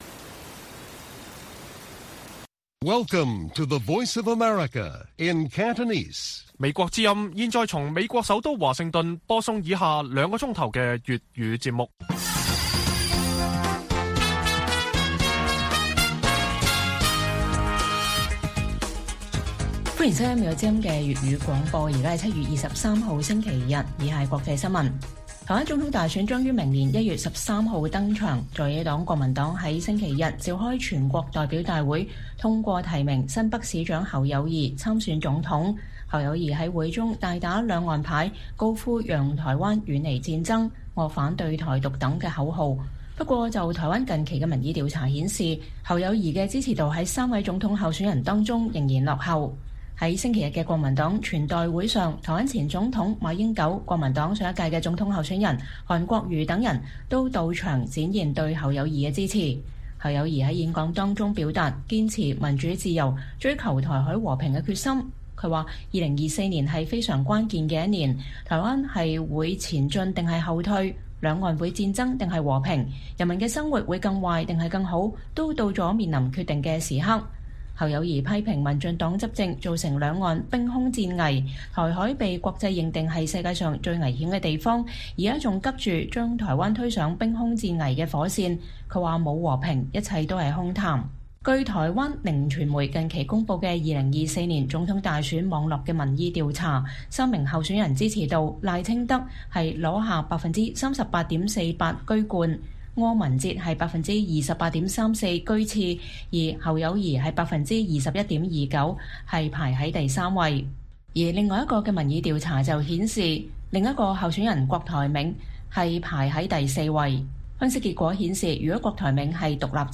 粵語新聞 晚上9-10點：台灣國民黨召開全代會 總統候選人侯友宜高喊“讓台灣遠離戰爭”